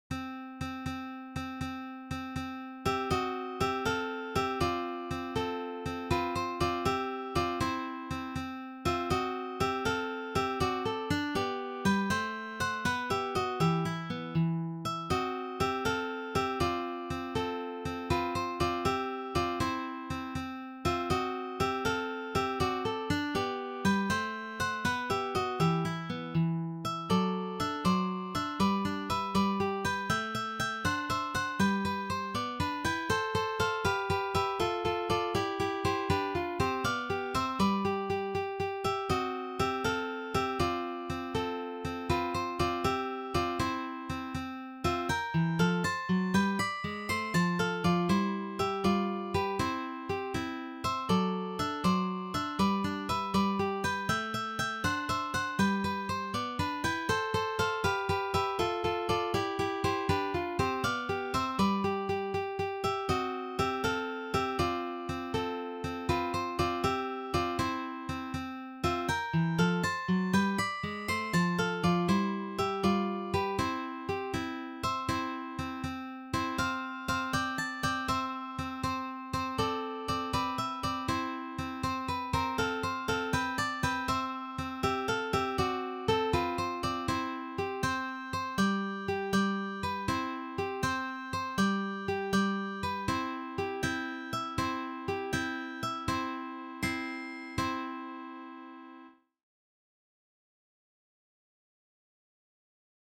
arranged for three guitars
This Classical selection is arranged for guitar trio.